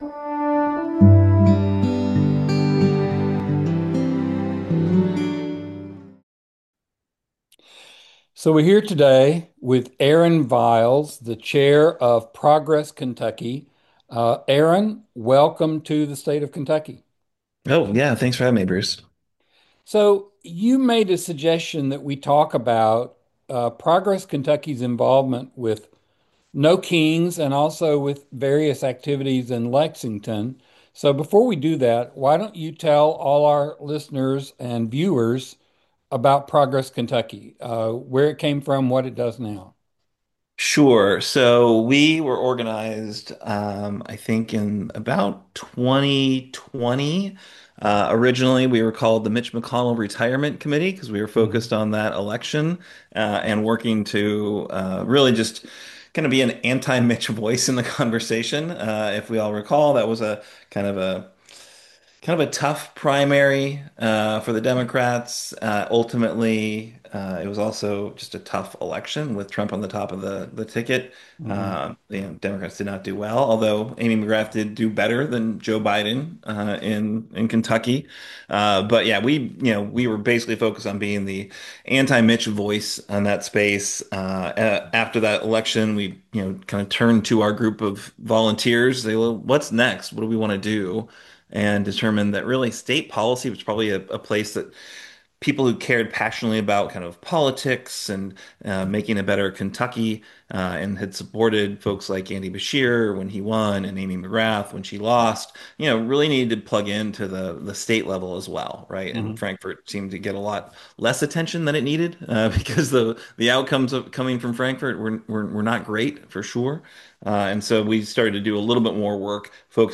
Interviews with persons and organizations having a positive effect on the Bluegrass state.